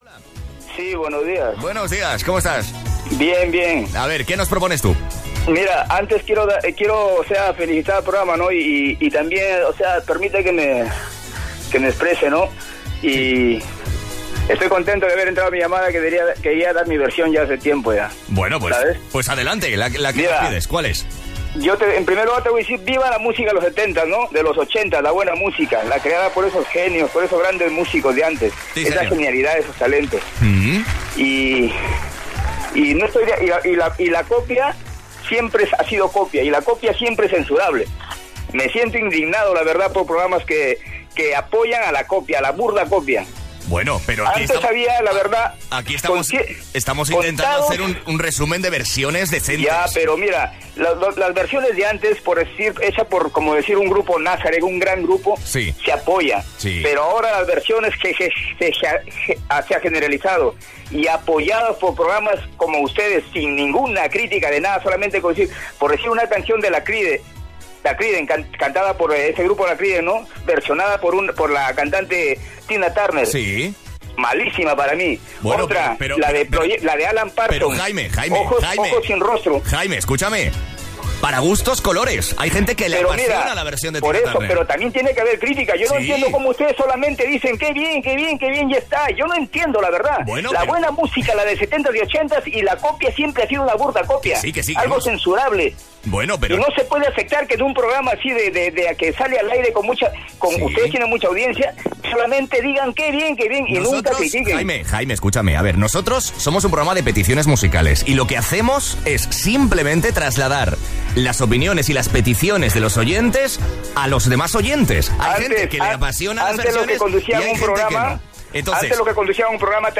Llamada Classic Box: Indignación con los Covers